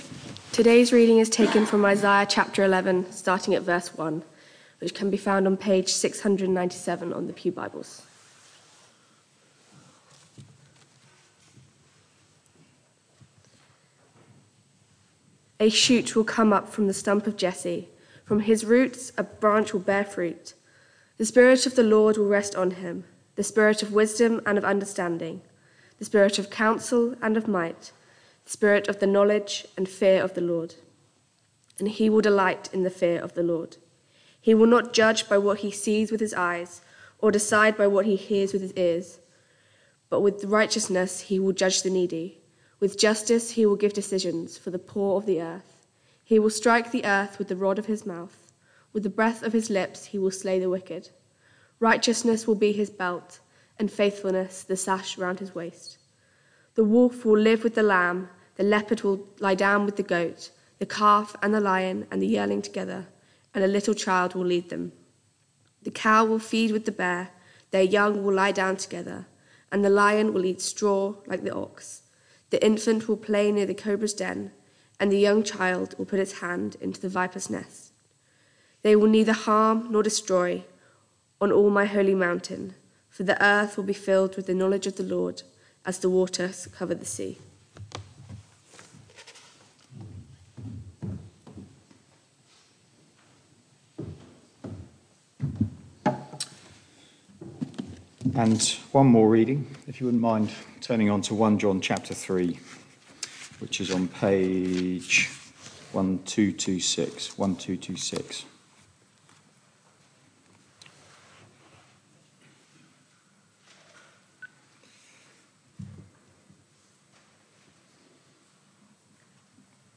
Media for Barkham Morning Service on Sun 24th Dec 2023 10:00
Reading & Sermon